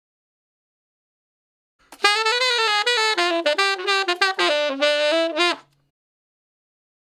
066 Ten Sax Straight (D) 08.wav